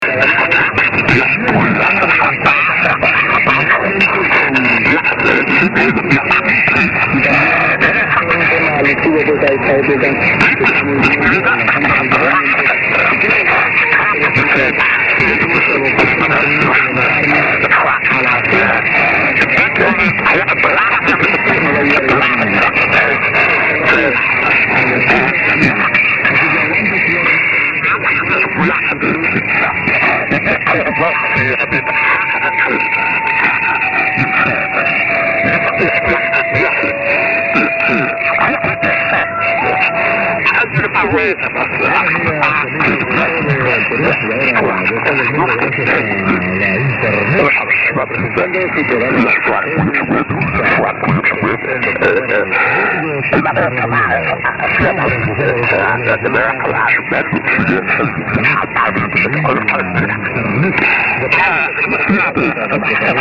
I have been quite surprised by the signal from this morning's 0400 file.
unid ss with distinctive little tune about 0358 1/5